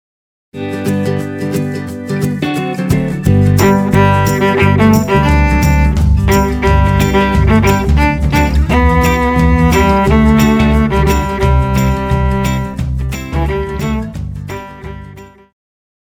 Pop
Viola
Band
Instrumental
Rock
Only backing